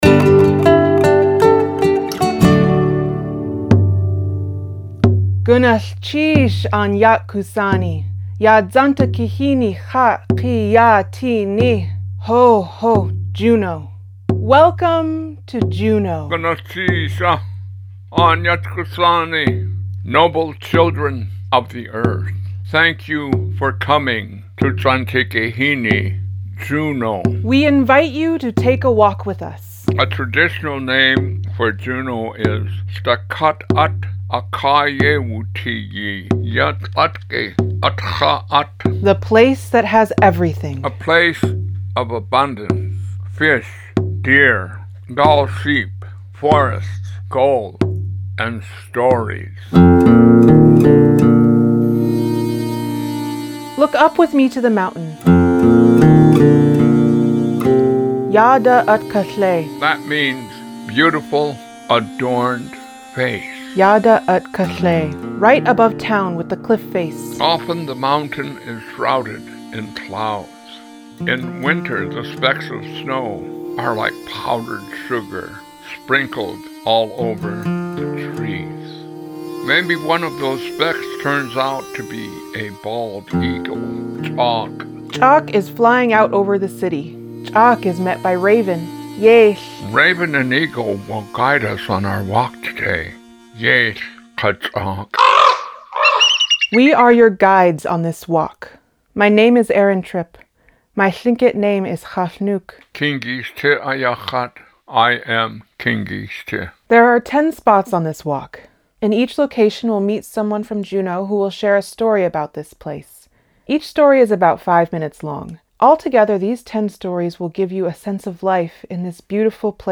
• The introductions you hear in this audio story follow traditional Tlingit protocols: identifying one’s name, moiety, kwáan, clan, clan house, and the names of one’s mother and maternal grandmother; and, honoring one’s father by sharing his name and clan.